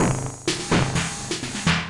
鼓声循环 " Hell1
描述：简单的工业鼓循环
Tag: 工业